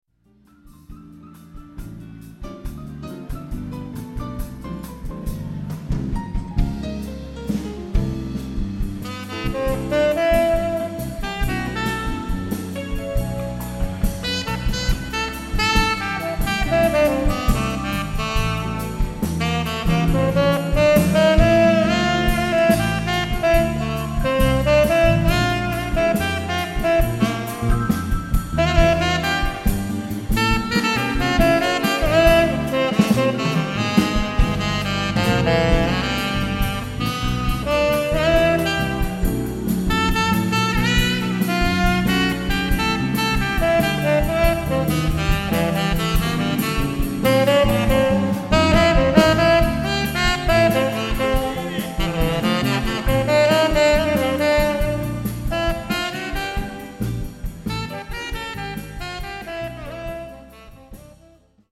on tenor